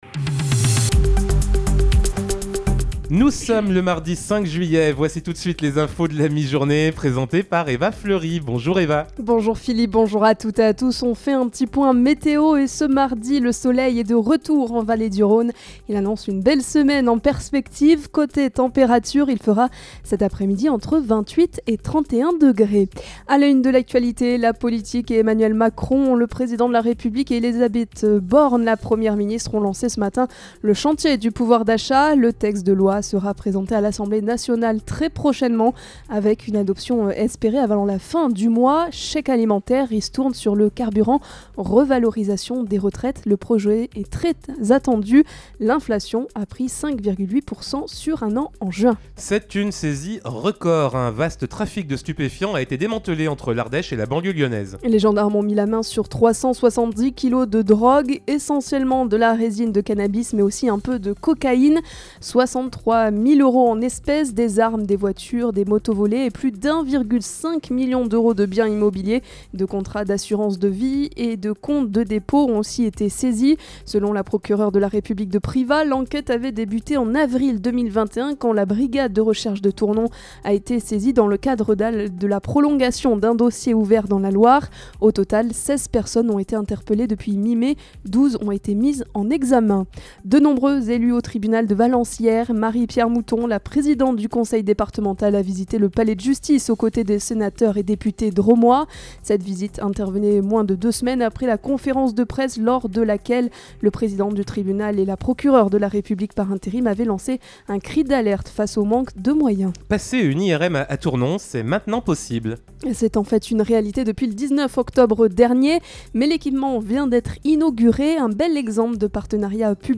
in Journal du Jour - Flash
Mardi 05 Juillet 2022: Le journal de 12h